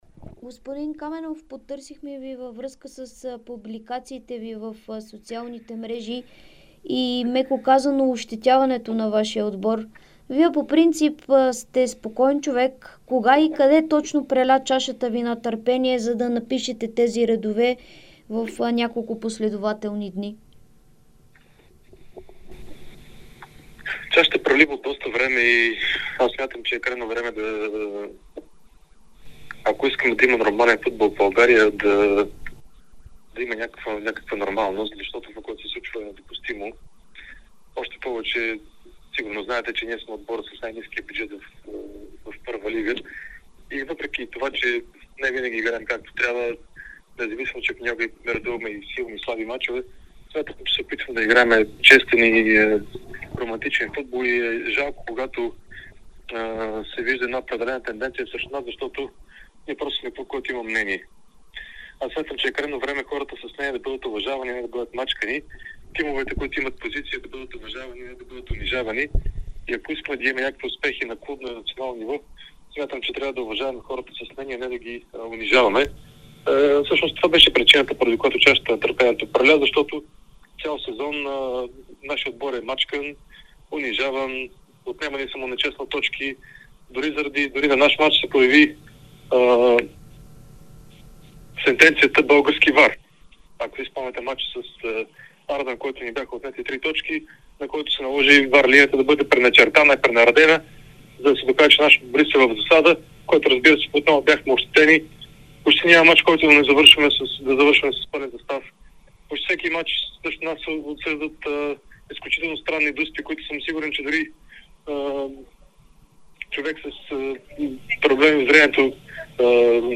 Кметът на Враца Калин Каменов даде ексклузивно интервю пред Дарик радио и dsport, в което той коментира своите коментари в социалните мрежи, в които з...